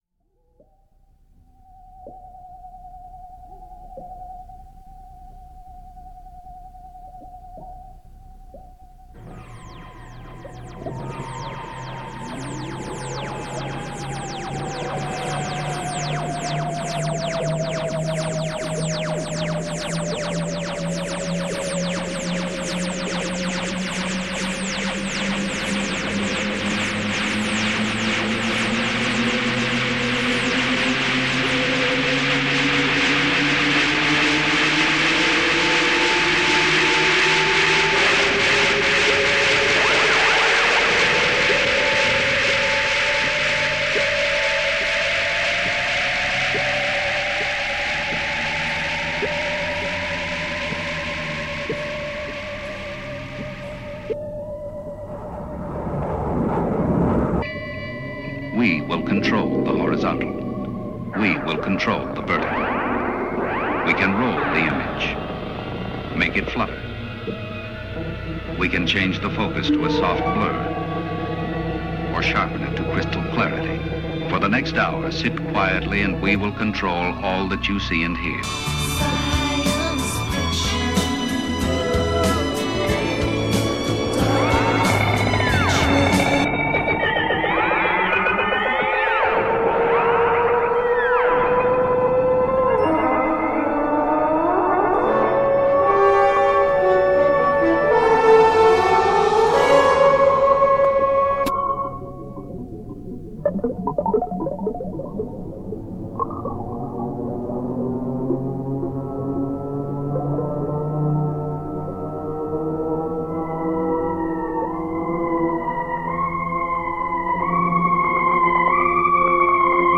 Ambient Avant-Garde Pop